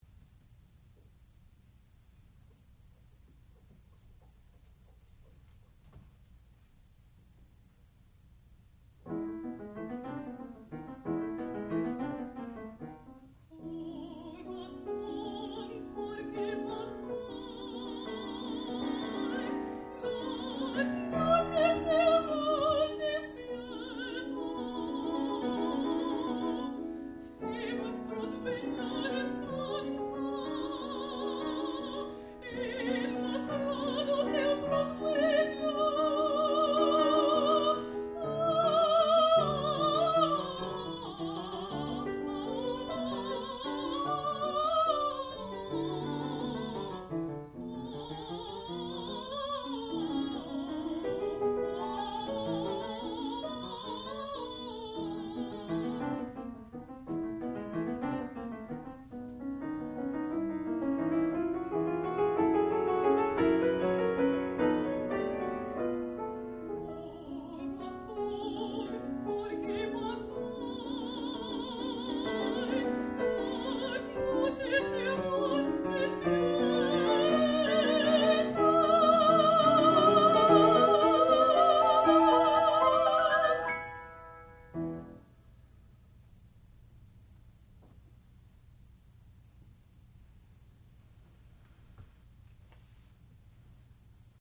sound recording-musical
classical music
piano
mezzo-soprano
Master's Recital